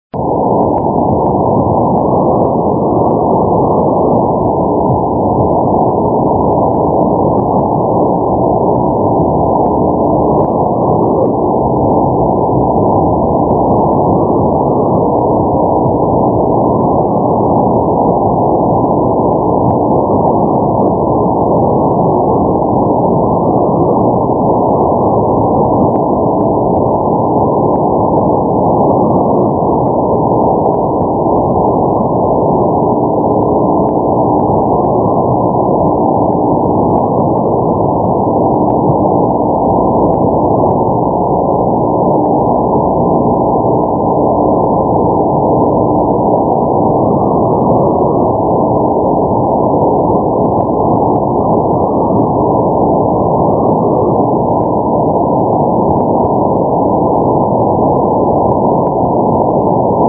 すべて名古屋市内の自宅での音なので人工ノイズ付です。（HI!)
弱弱しい音ではあったが何とか確認できた。
29秒には"1690"と出て,34秒には女性のジングルで"WVON"のようです。